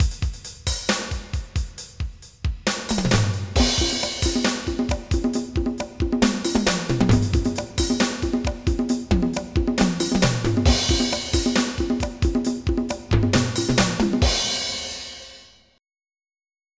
GMDRUMS.mp3